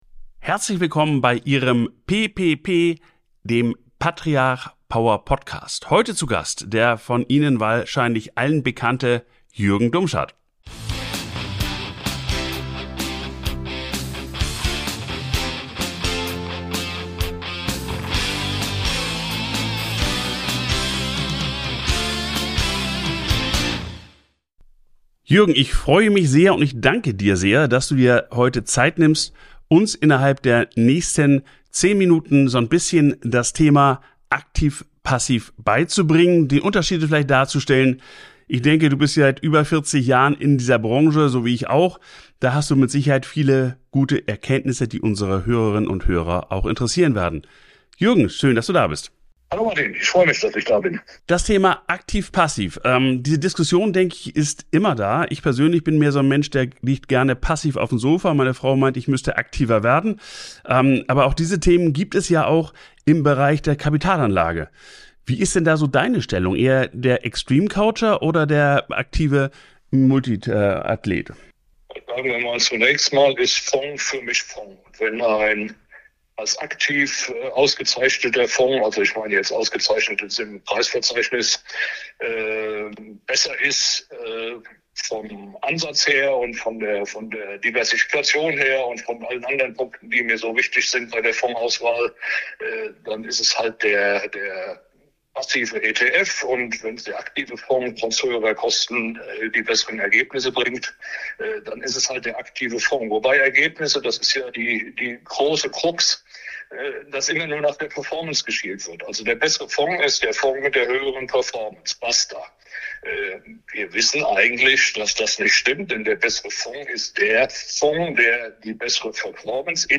Power Talk. In nur zehn Minuten sprechen die beiden über aktive und passive Fonds, über ETFs und die entscheidende Frage, was einen wirklich erfolgreichen Fonds auszeichnet.